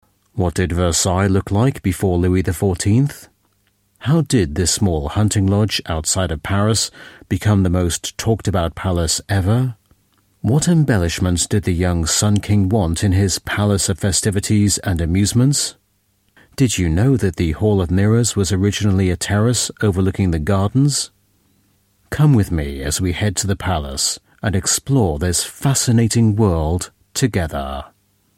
Demos in Audioproduktionen
Doku - Versailles